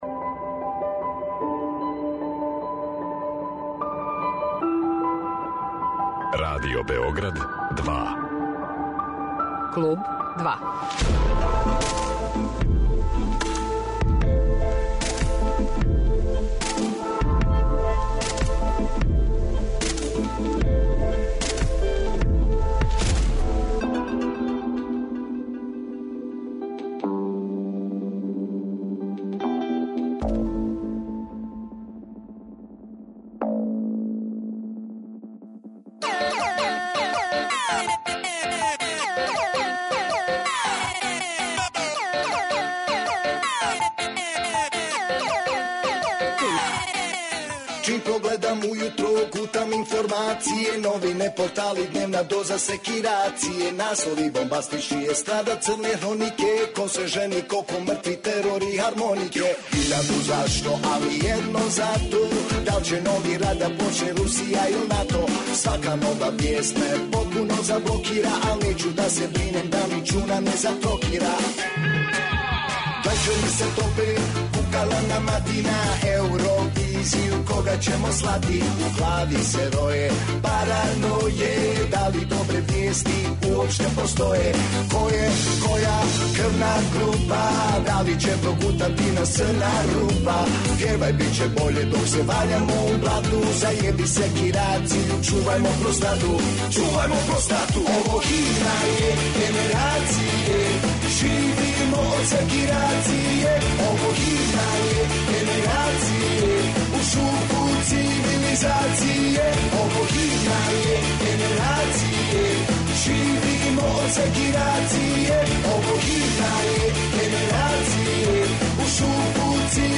Разговор је први пут емитован у јануару 2018. године, а данас ћете га поново чути поводом сутрашњег концерта Дубиоза колектива на Ташу .